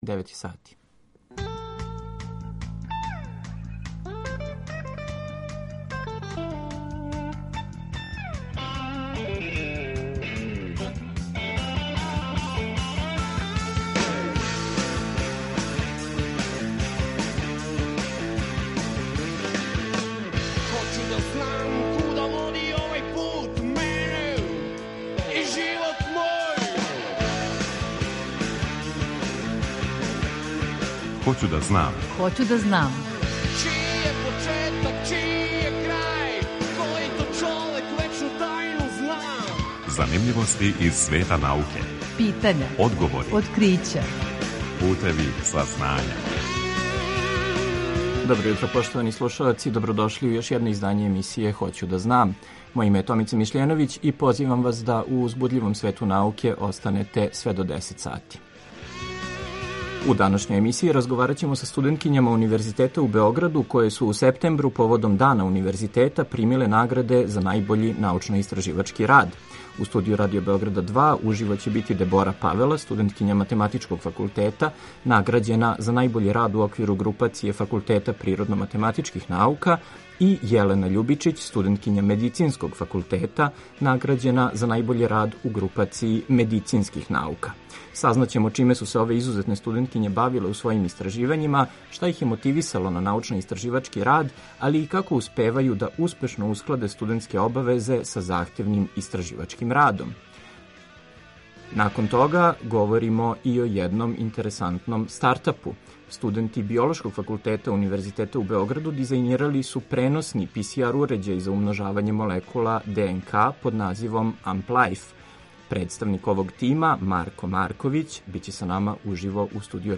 У данашњој емисији разговараћемо са студенткињама Универзитета у Београду које су у септембру, поводом Дана Универзитета, примиле награде за најбољи научно-истраживачки рад.